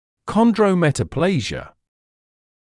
[ˌkɒndrə(u)ˌmetə’pleɪzɪə][ˌкондро(у)ˌмэтэ’плэйзиэ]хондрометаплазия